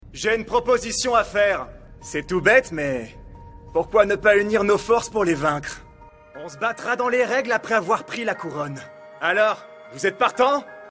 Voix off
Bande démo doublage
16 - 35 ans - Baryton